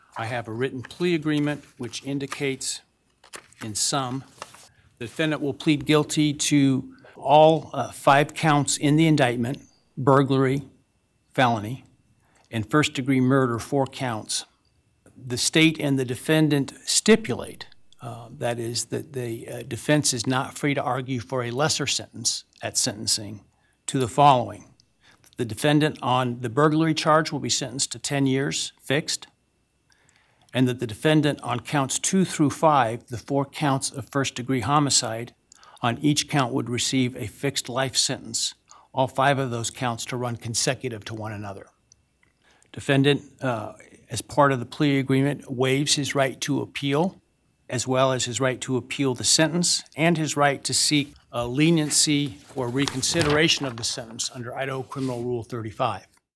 During a change of plea hearing today (Wed) in Boise, presiding Judge Steven Hippler explained what the guilty plea means moving forward.
hippler-plea-1.mp3